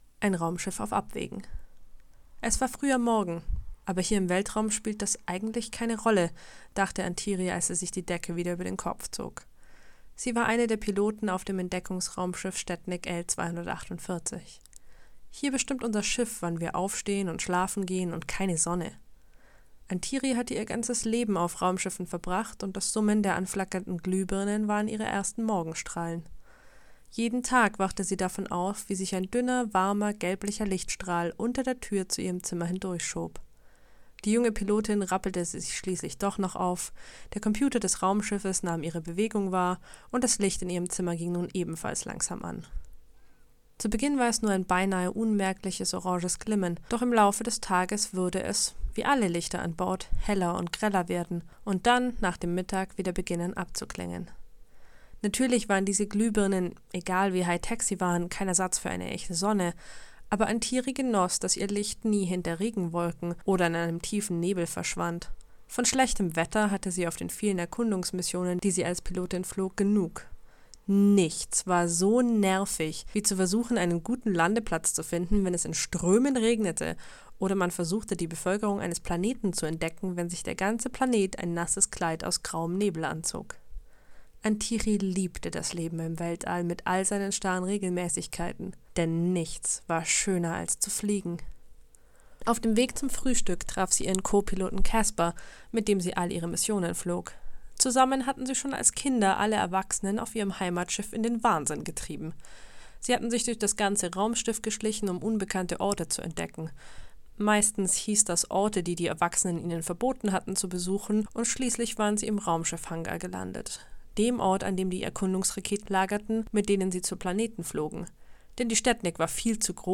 Hier findest du nun meine Geschichte für den ersten Abend: